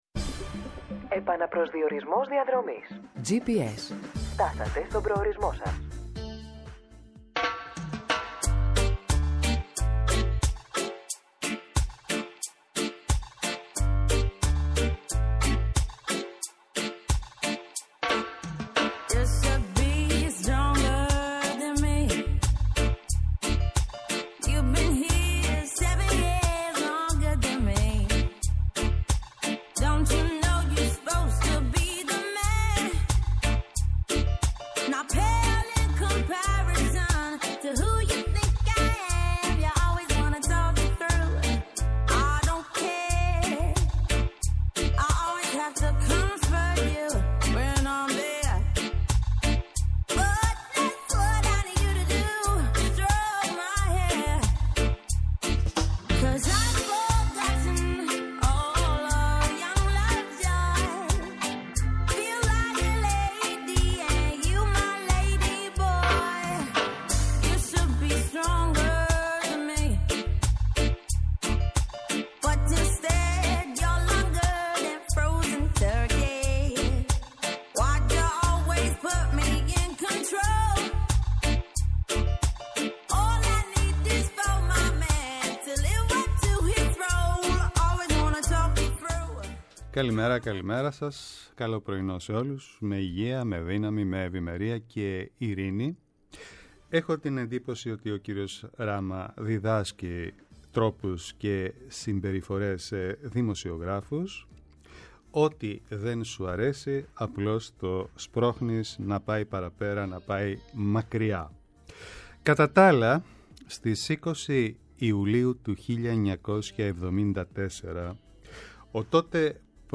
Από Δευτέρα έως και Πέμπτη (10:00 – 11:00), σας «οδηγεί» μέσα από τις διαδρομές της πολιτικής αλλά και της οικονομίας στην ανάγνωση των γεγονότων από μια πιο διερευνητική ματιά για να μπορείτε να είστε πολύπλευρα ενημερωμένοι και άποψη για τις εξελίξεις. ΠΡΩΤΟ ΠΡΟΓΡΑΜΜΑ